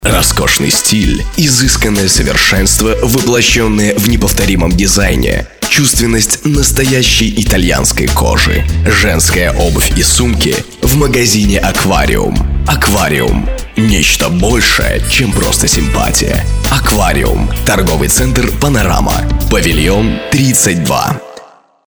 по желанию заказчика - солидный и спокойный голос